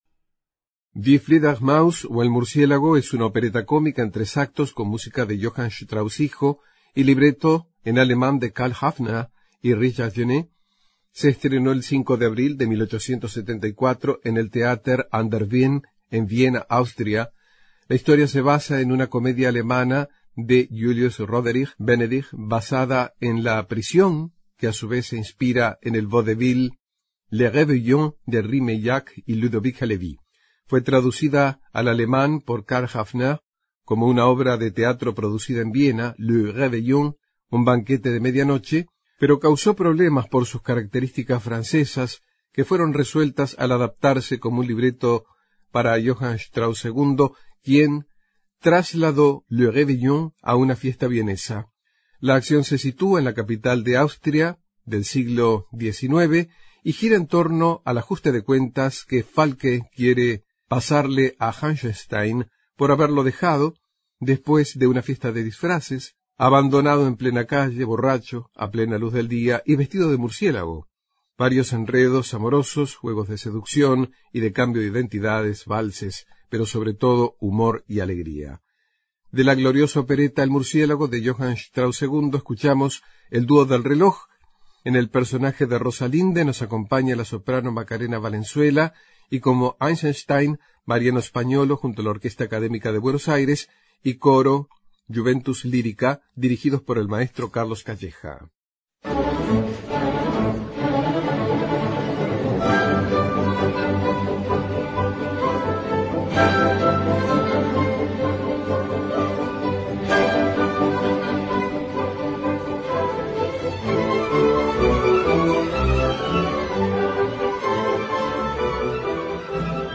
Los grandes Éxitos de estos populares géneros de la lírica.